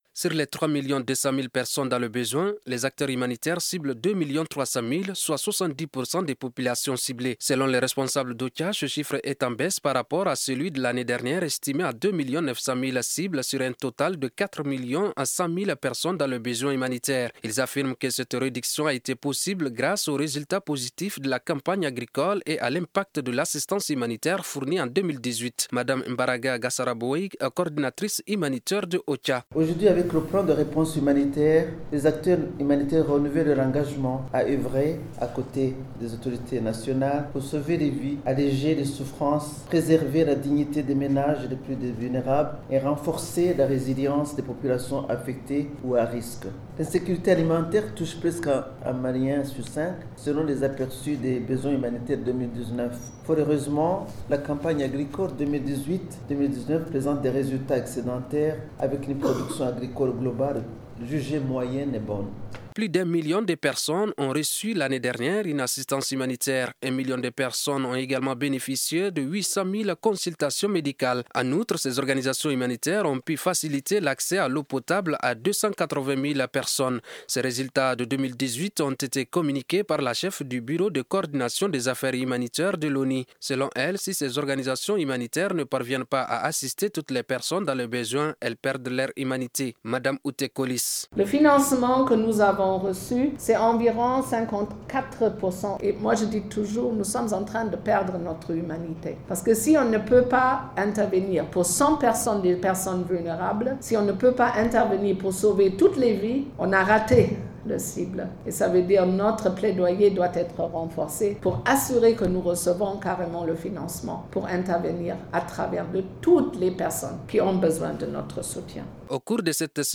C’était lors de la cérémonie du lancement du plan de réponse humanitaire pour le Mali.